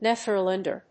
発音記号
• / néðɚl`ændɚ(米国英語)
• / néðəl`ændə(英国英語)